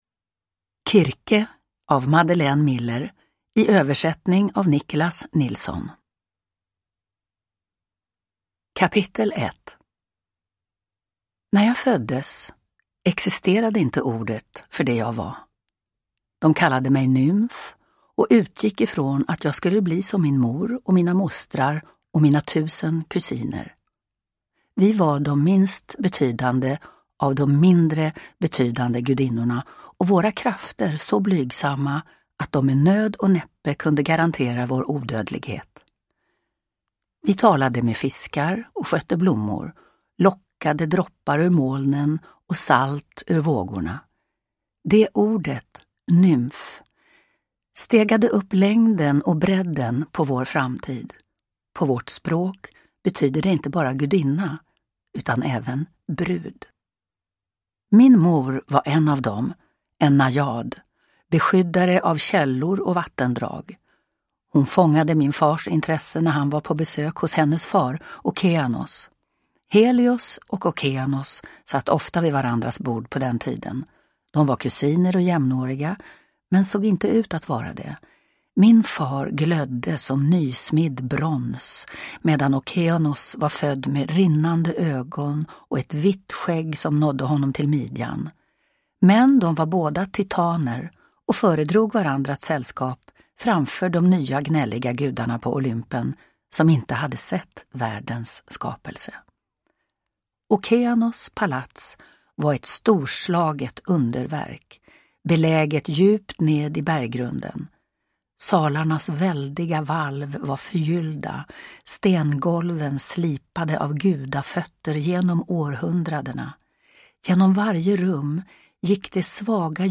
Produkttyp: Digitala böcker
Uppläsare: Lena Endre